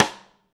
rim shot ff.wav